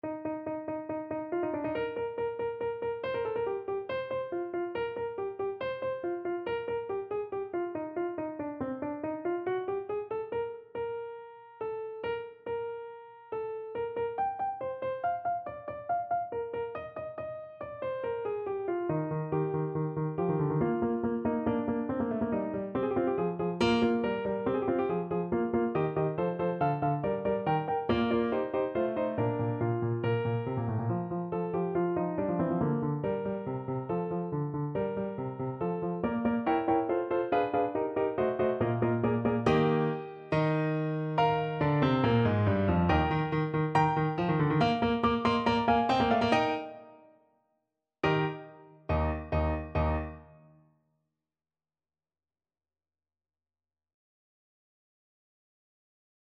Eb major (Sounding Pitch) (View more Eb major Music for Violin )
= 140 Allegro (View more music marked Allegro)
Classical (View more Classical Violin Music)